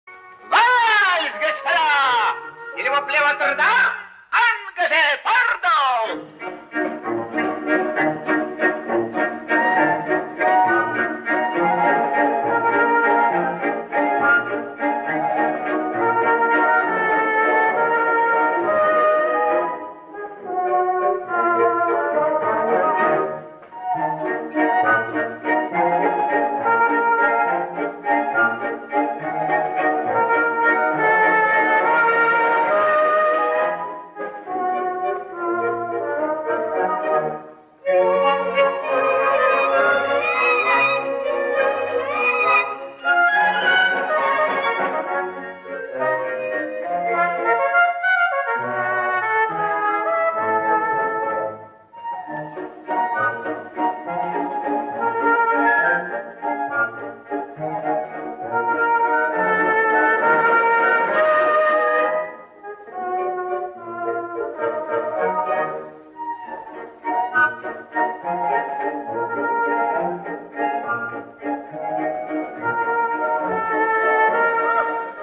Похож на наш, дореволюционный.